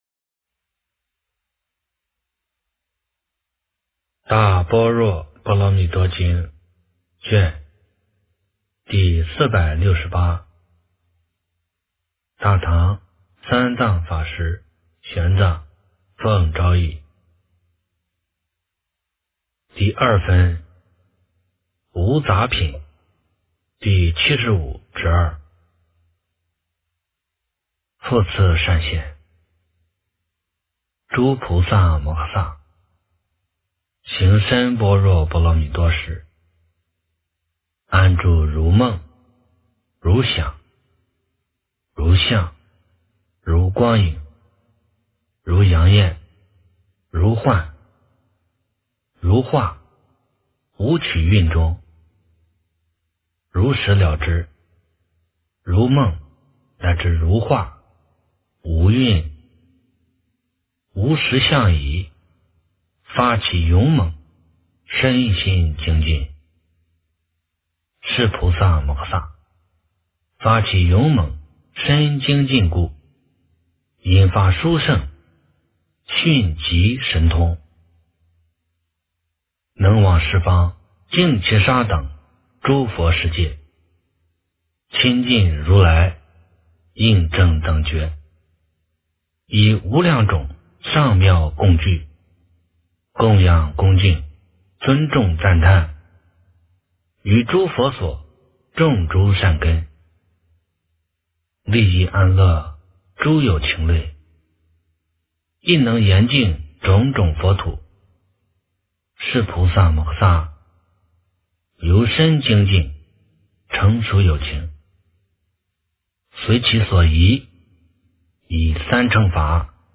大般若波罗蜜多经第468卷 - 诵经 - 云佛论坛